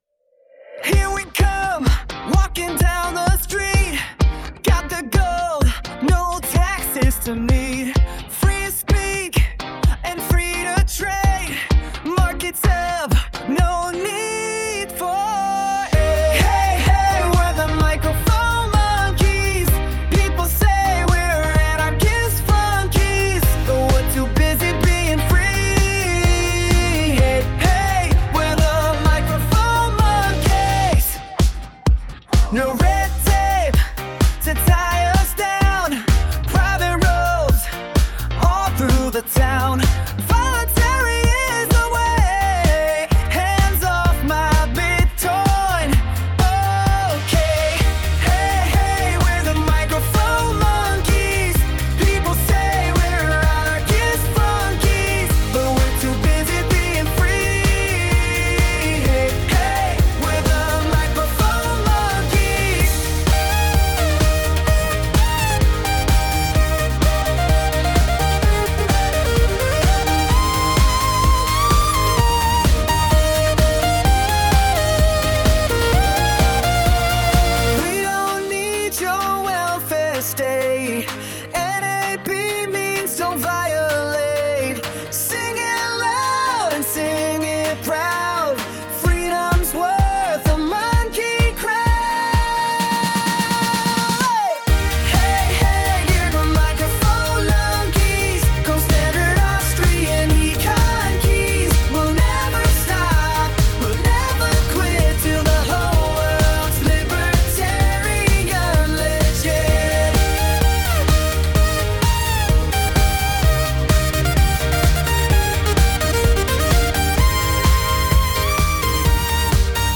Funny, Up Beat son